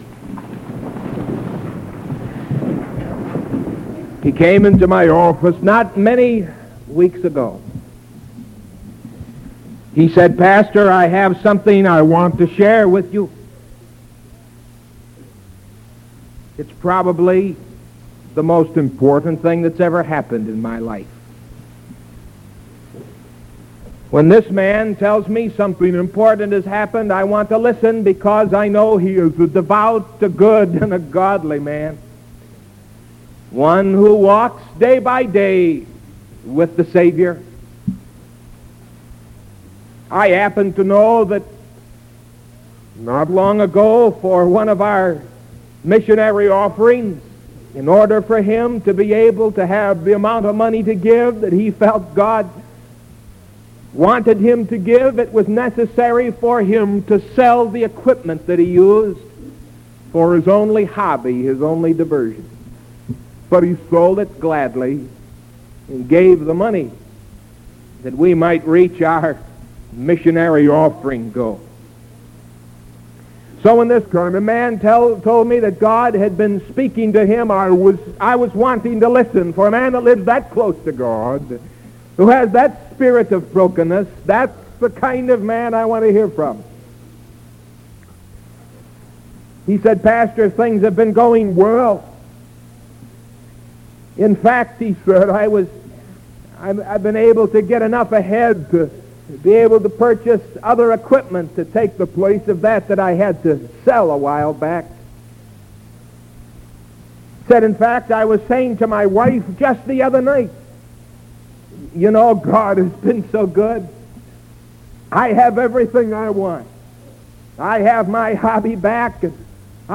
Sermon November 11th 1973 AM